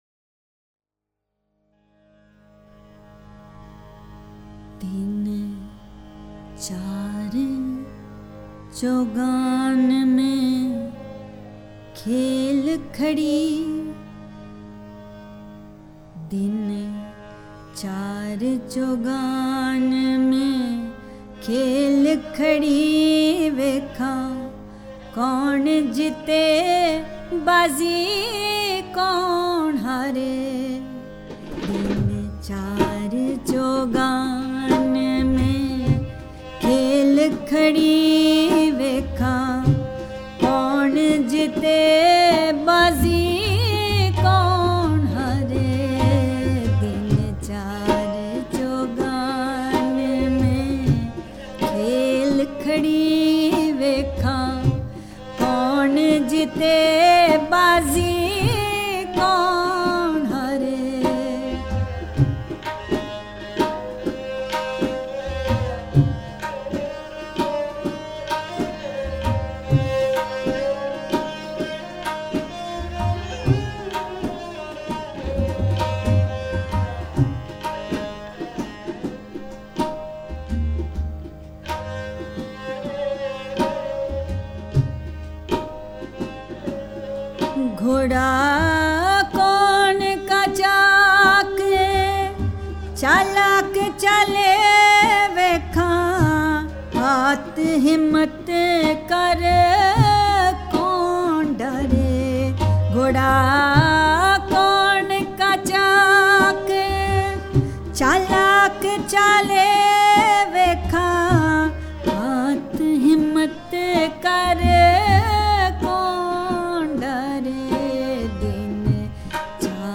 Punjabi Sufiana Kalam